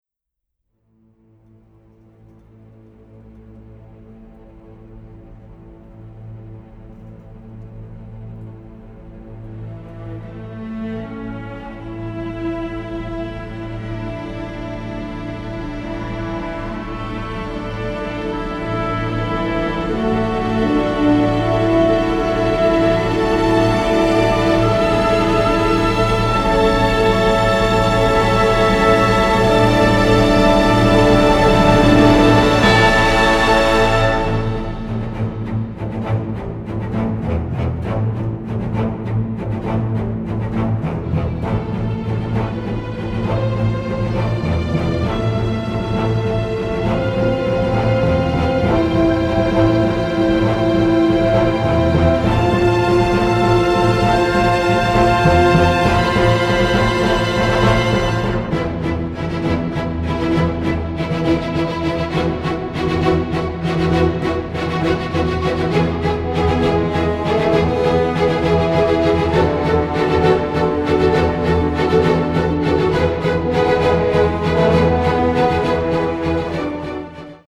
symphonic, varied, funny and emotional